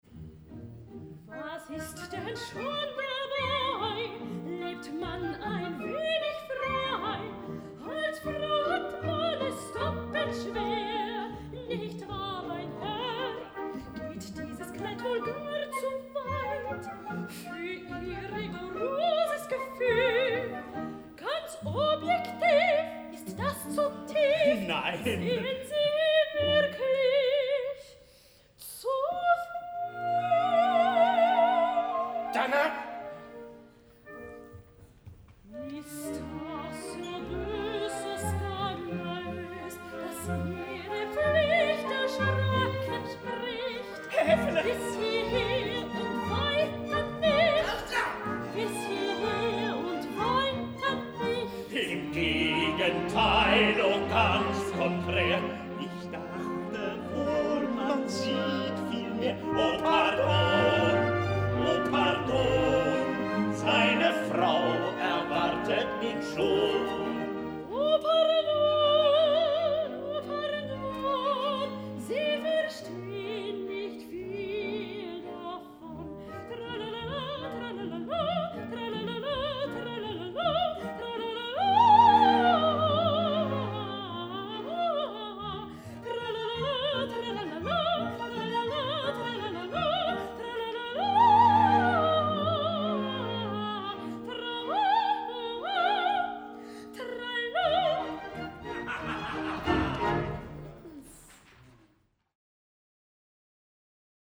Terzett Pauline
Orchester des Staatstheaters am Gärtnerplatz